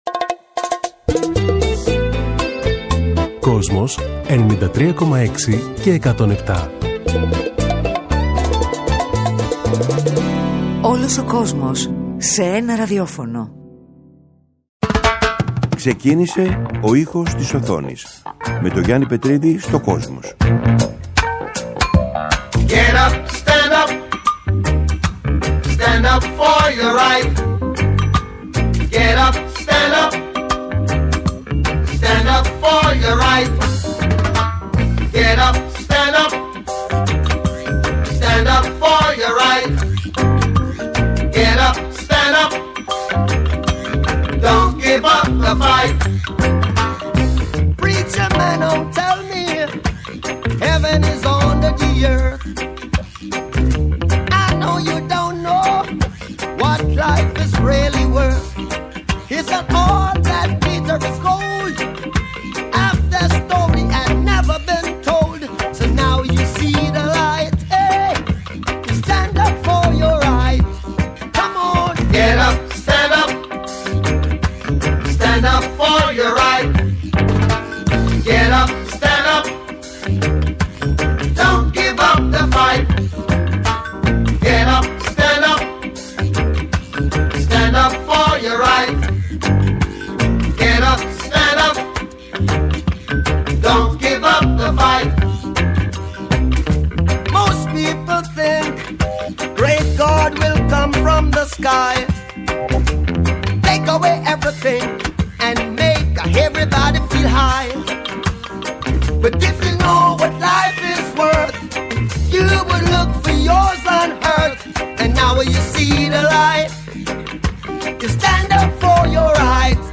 Κάθε Κυριακή 18:00-19:00 ο Γιάννης Πετρίδης παρουσιάζει μία σειρά αφιερωματικών εκπομπών για το Kosmos, με τον δικό του μοναδικό τρόπο.